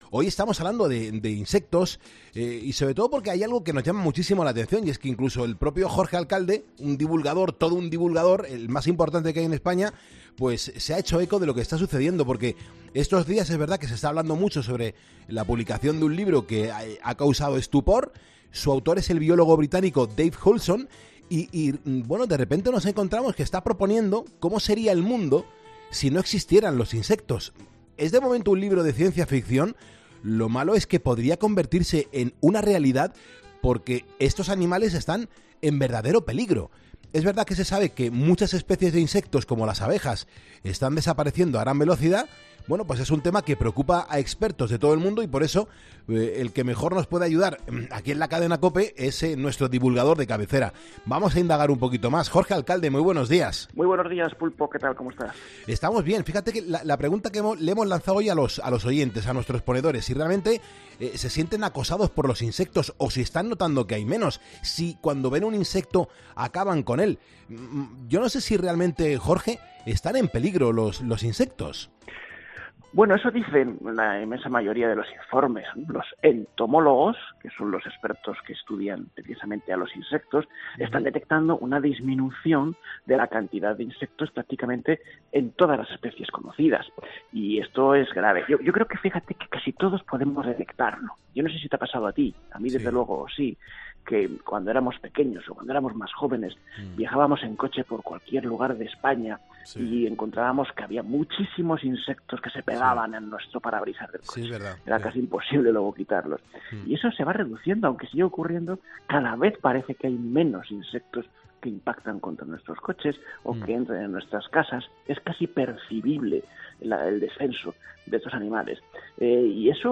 Jorge Alcalde explica en 'Poniendo las Calles' a Carlos Moreno 'El Pulpo' por qué estos animales están en verdadero peligro